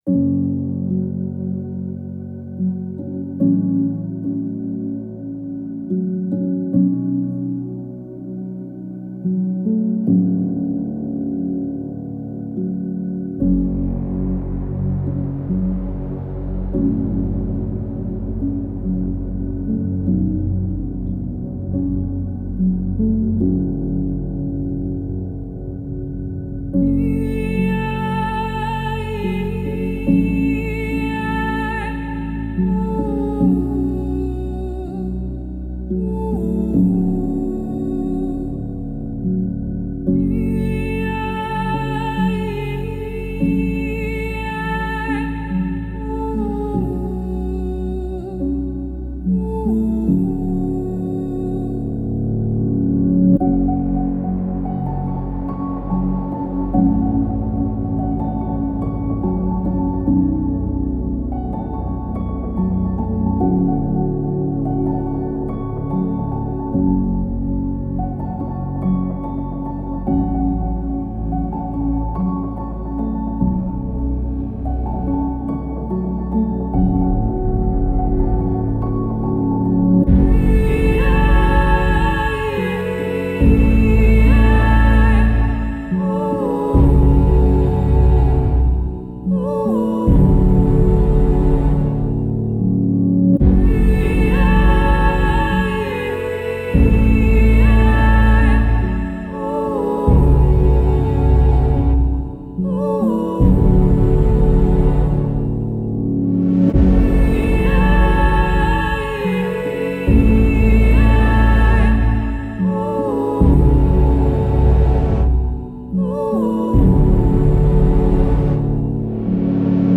Melancholic female vocal textures and subtle piano.